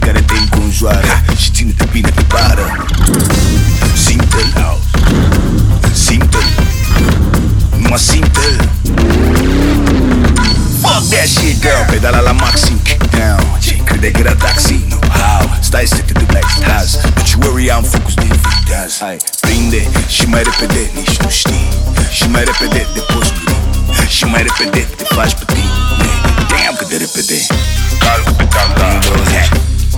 Жанр: Поп музыка / R&B / Соул
Afro-Pop, African, R&B, Soul